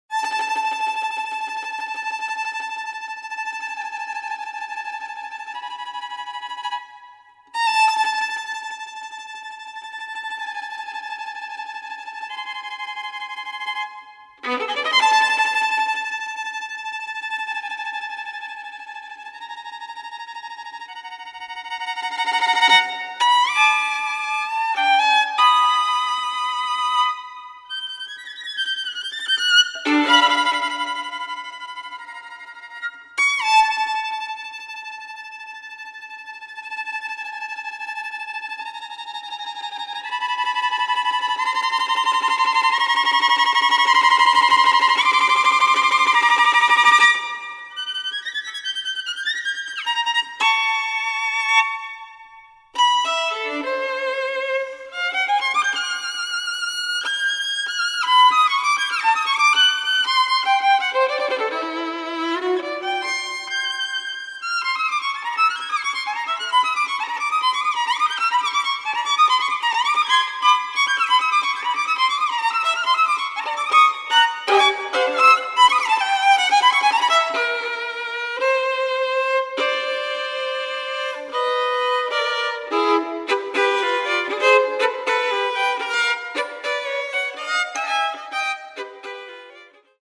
quintet for clarinet and string quartet
for flute, violin, cello
for solo violin